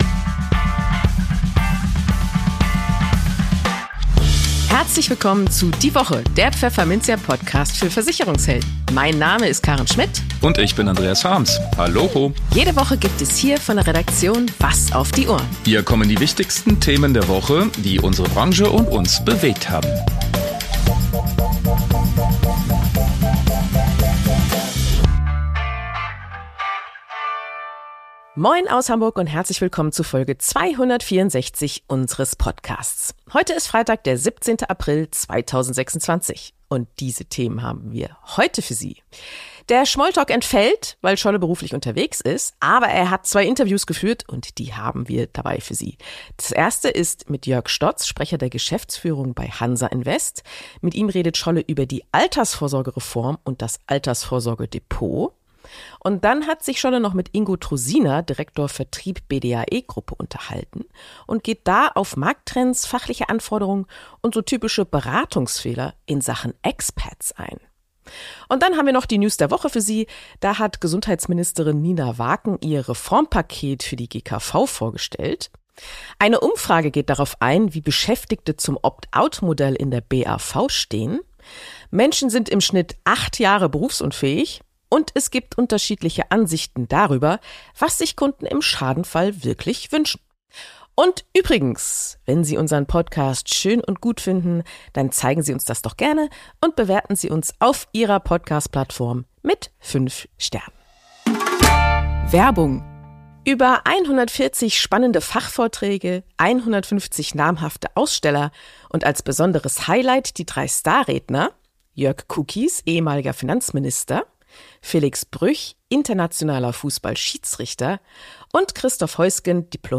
Dafür haben wir zwei Interviews für Sie.